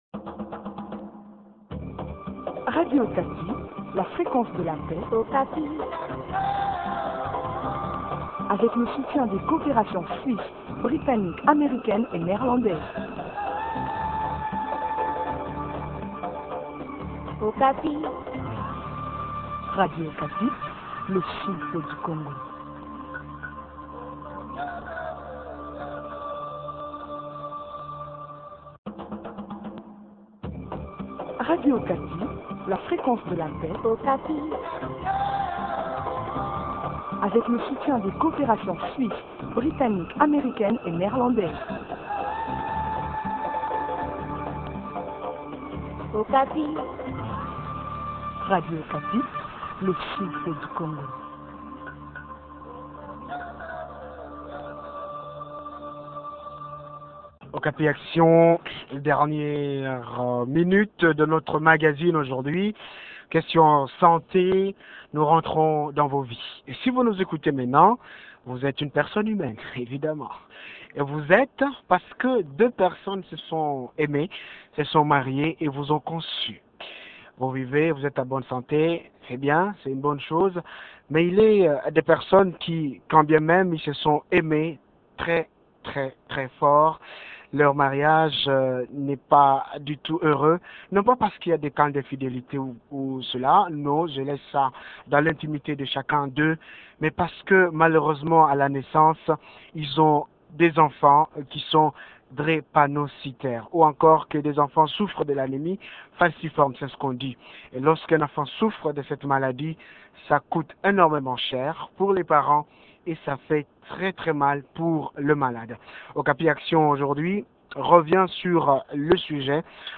Témoignages émouvants, séparation dure.